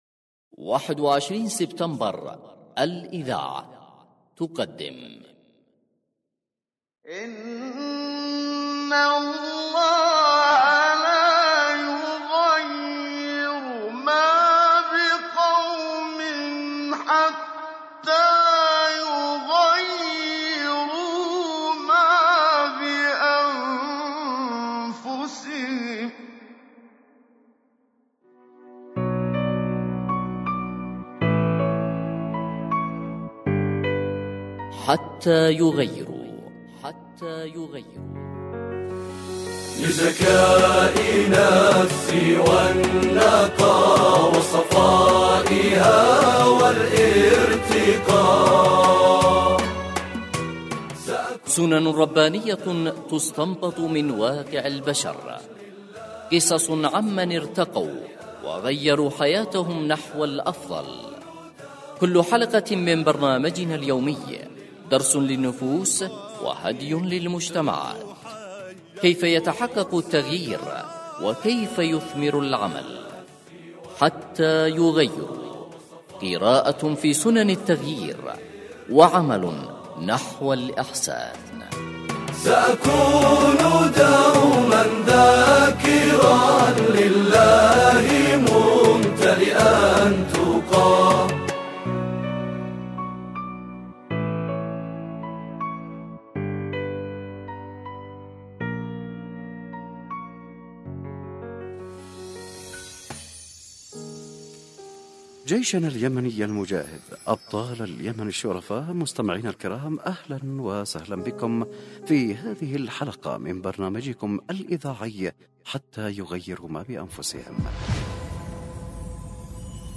الحلقة الأولى (2)حتى يغيروا ما بأنفسهم، برنامج إذاعي يتطرق الى سنن الله في التغيير من خلال تغيير النفوس وارتباط ذلك بسنة الله في التغيير الذي لا يحابي أحد ولا يجامل أحد، مع التطرق الى النصوص القرآنية والنصوص التي قدمها اعلام الهدى في المشروع القرآني.